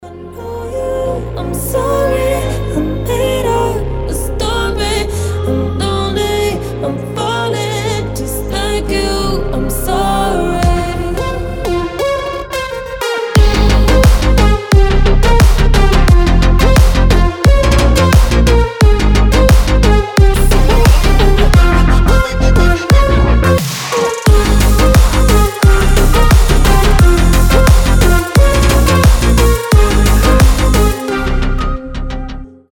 Electronic
Midtempo
красивый женский голос